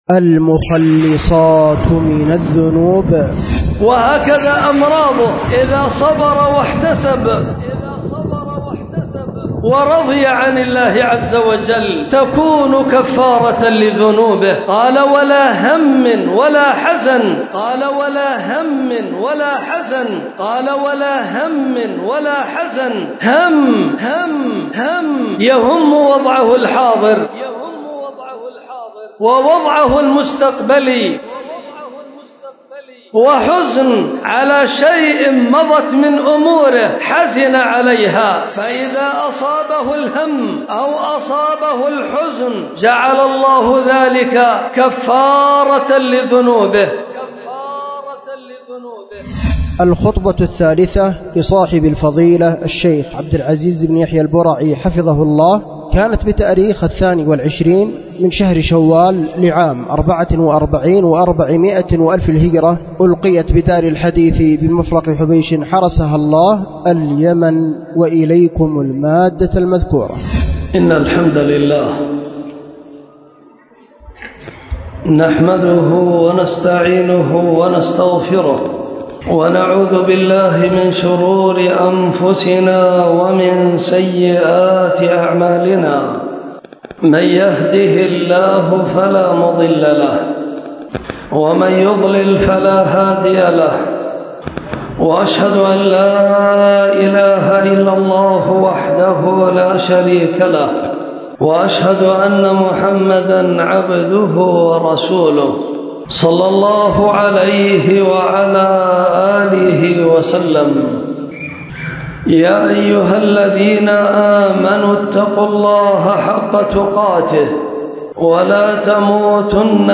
ألقيت بدار الحديث بمفرق حبيش